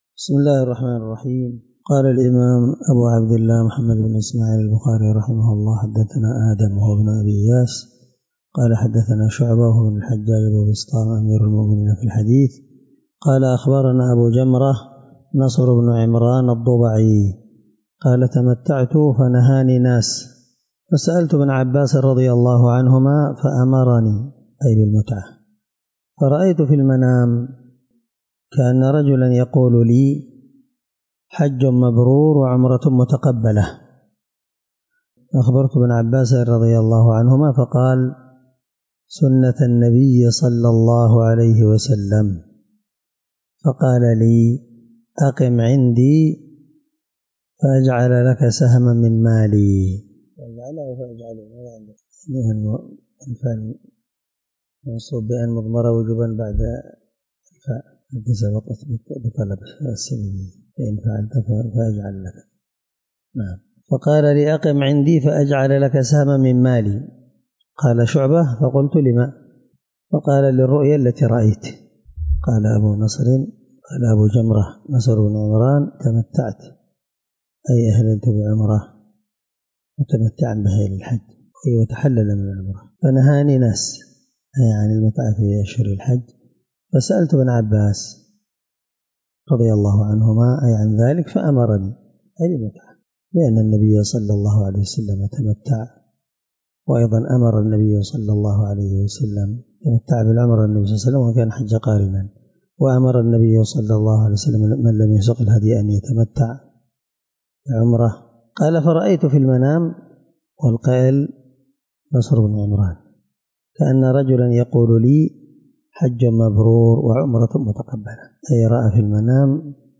الدرس23 من شرح كتاب الحج حديث رقم(1567-1569 )من صحيح البخاري